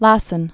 (lăsən)